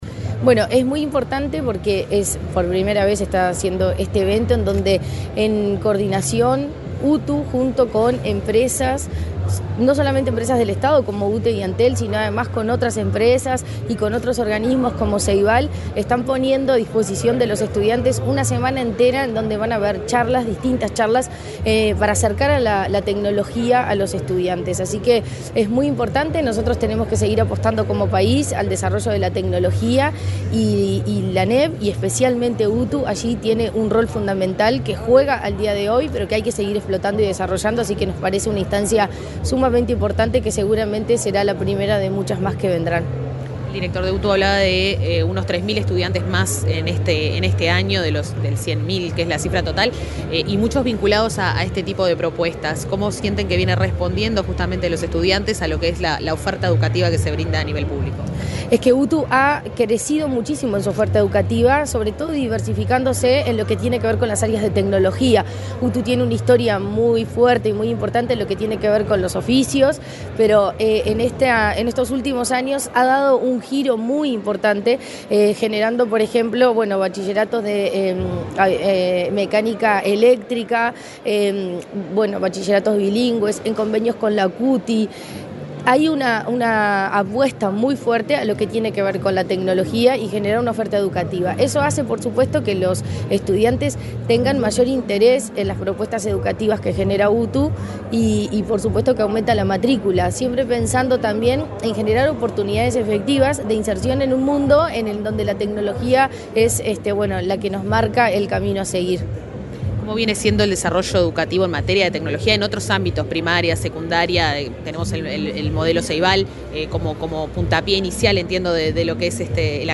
Entrevista a la presidenta de la ANEP, Virginia Cáceres
Este lunes 22 en Montevideo, la presidenta de la Administración Nacional de Educación Pública (ANEP), Virginia Cáceres, dialogó con Comunicación